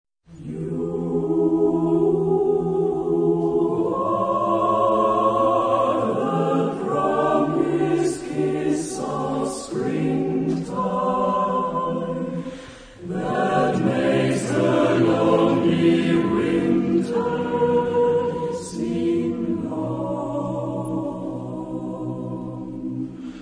Caractère de la pièce : swing ; doux ; syncopé ; lent
Type de choeur : SSAATTBB  (8 voix mixtes )
Tonalité : la bémol majeur